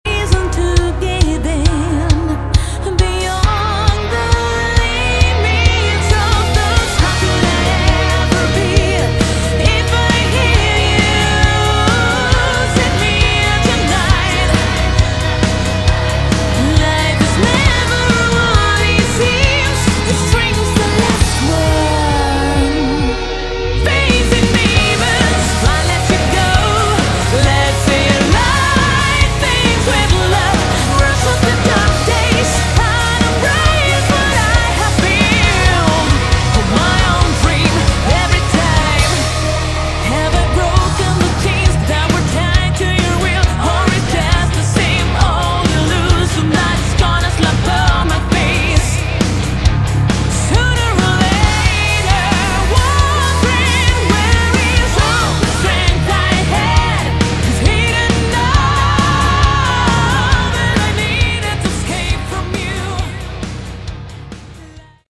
Category: Melodic Metal
vocals
keyboards
drums
guitars
bass